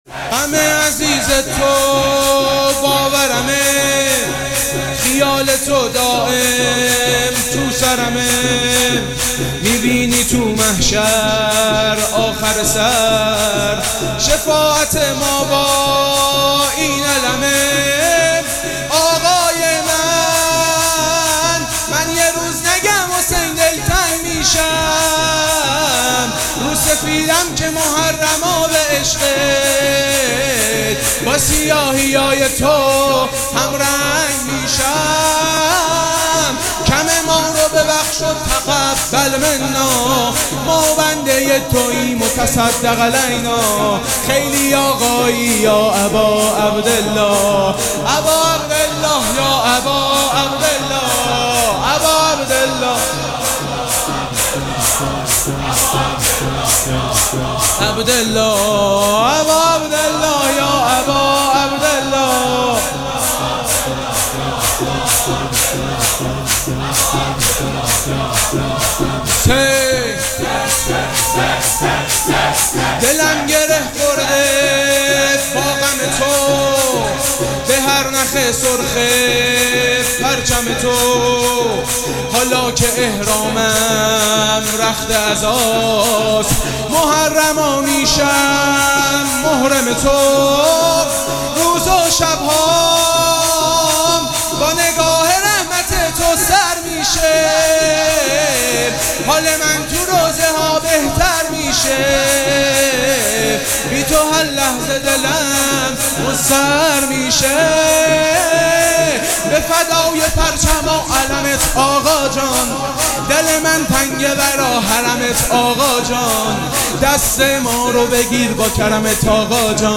مراسم عزاداری شب هفتم محرم الحرام ۱۴۴۷
شور
مداح